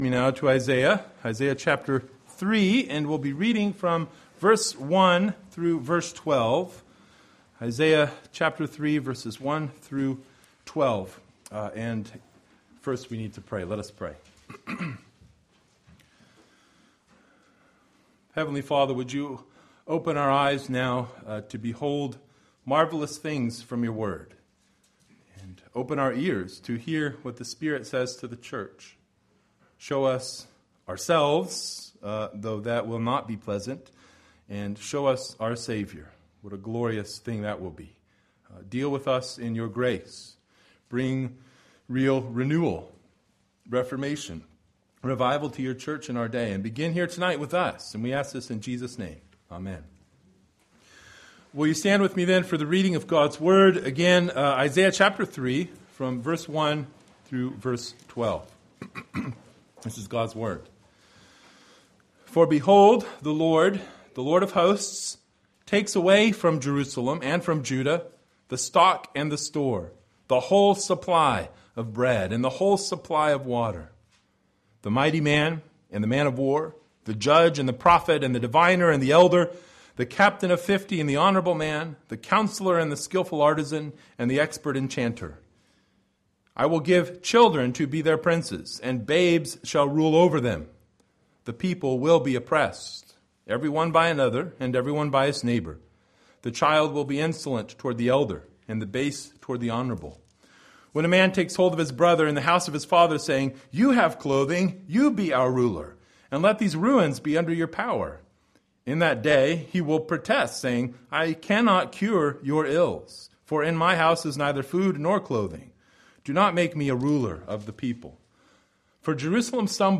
Passage: Isaiah 3:1-13 Service Type: Sunday Evening